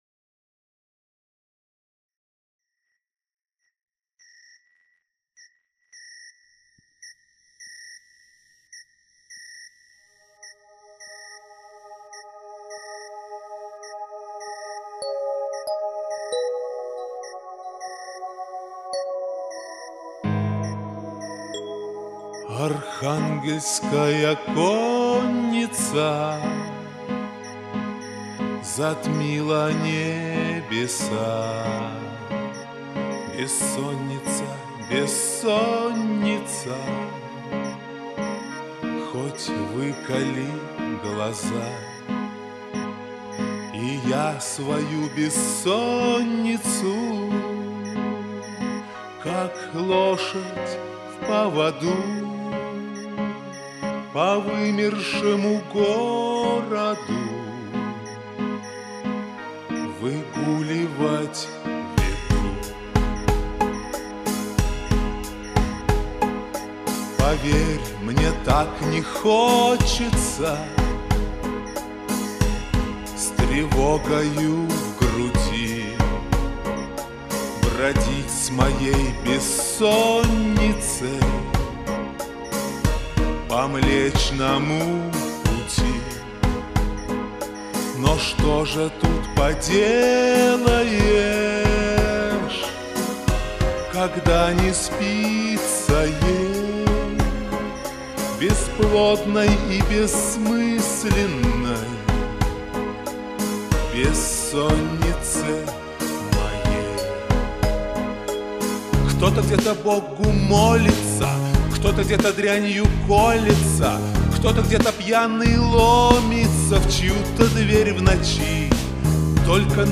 бодренькой, но симпатичной аранжировке настолько очаровала
а красивый шансон с красивыми стихами....Такой как эта